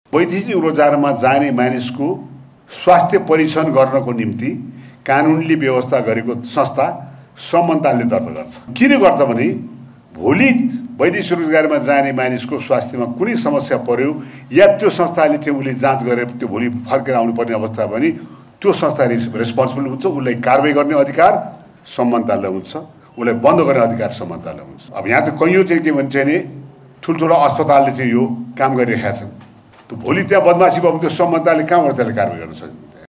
त्रिभुवन विश्वविद्यालय जनसङ्ख्या अध्ययन केन्द्रीय विभाग र आप्रवासनका लागि अन्तर्राष्ट्रिय सङ्गठन (आइओएम)ले अन्तर्राष्ट्रिय आप्रवासन दिवसका अवसरमा बुधवार आयोजना गरेको कार्यक्रममा श्रममन्त्री शरदसिंह भण्डारीले वैदेशिक रोजगारीमा जाने श्रमिकहरूको स्वास्थ्य परीक्षण गर्न एउटा छुट्टै संस्था स्थापना गरेर श्रम मन्त्रालय मातहत राख्न आवश्यकता रहेको बताए।